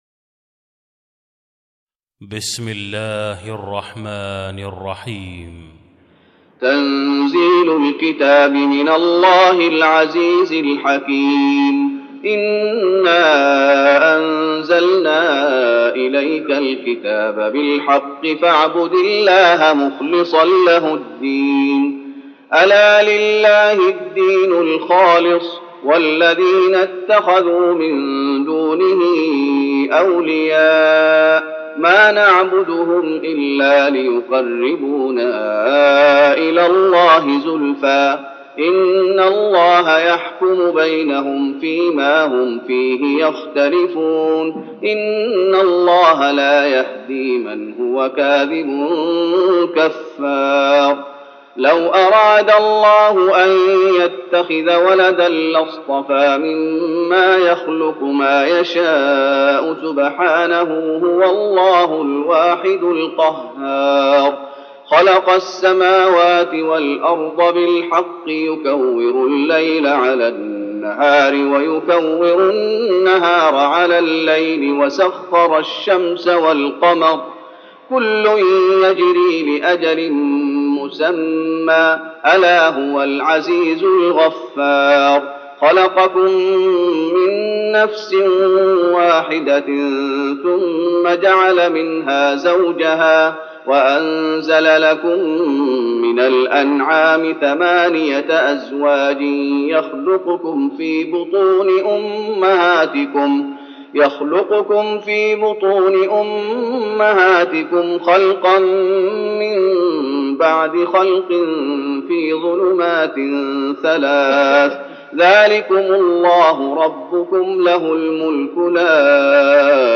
تراويح رمضان 1413هـ من سورة الزمر (1-31) Taraweeh Ramadan 1413H from Surah Az-Zumar > تراويح الشيخ محمد أيوب بالنبوي 1413 🕌 > التراويح - تلاوات الحرمين